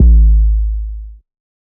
Metro 808 9.wav